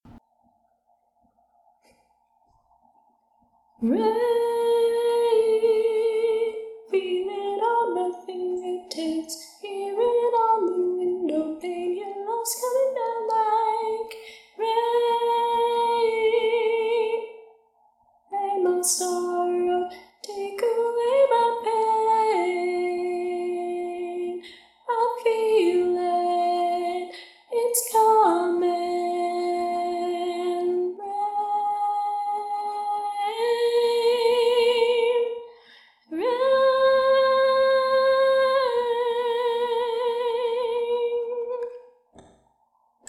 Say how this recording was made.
:-P I also apologize for the quality of the tracks.